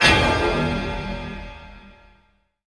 INDUSTRIAL.wav